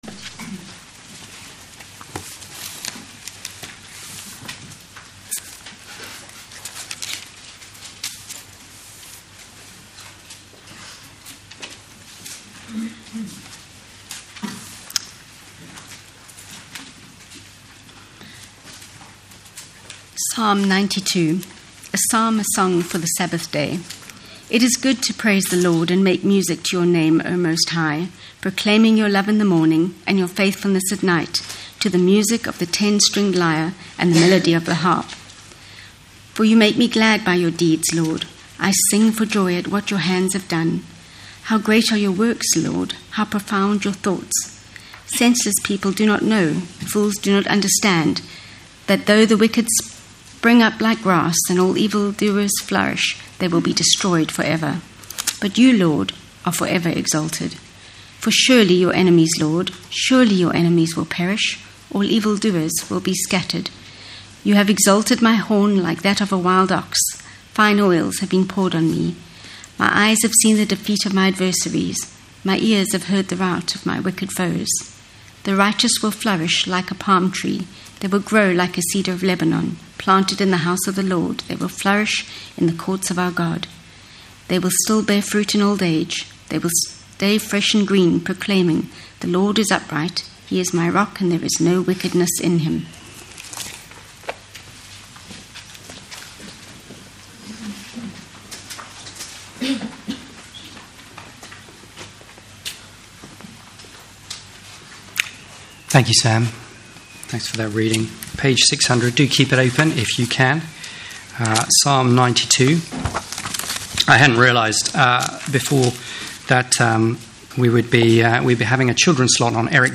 Over the summer our elders will be preaching from selected Psalms